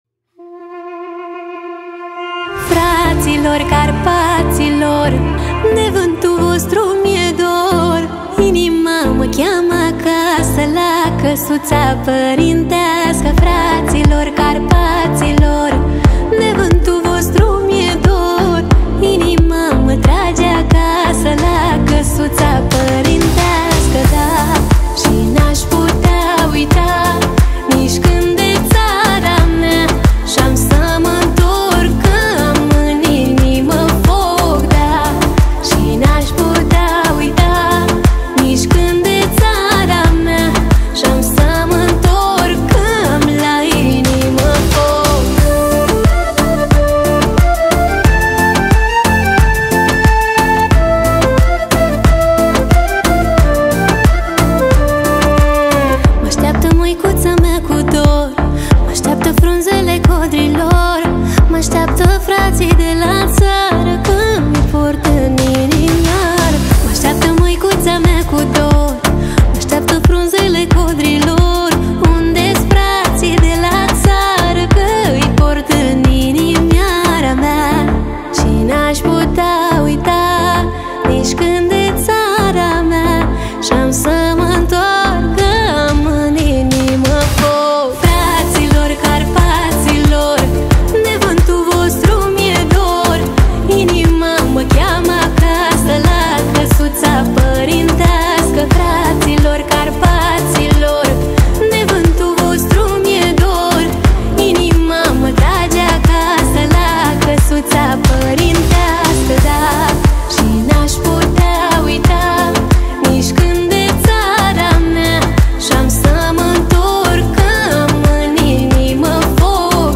соединяя традиционные мотивы с современным звучанием.